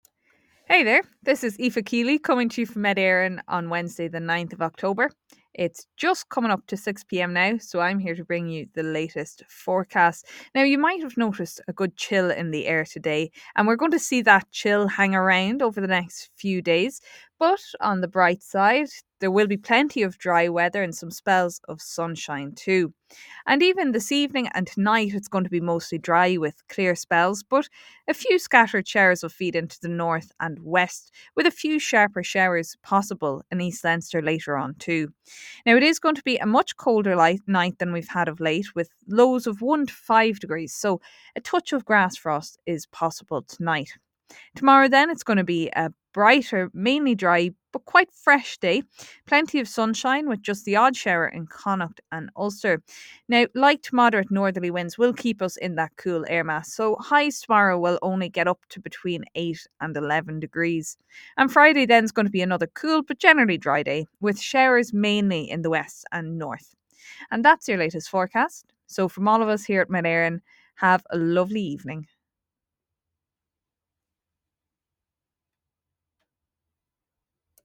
Weather Forecast from Met Éireann / Ireland's Weather 6pm Wednesday 9 October 2024